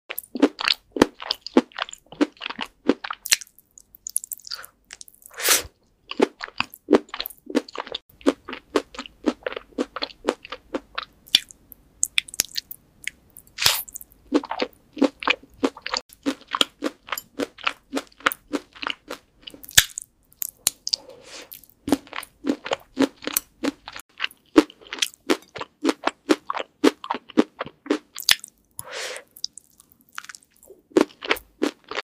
Biting into glowing frosted lava.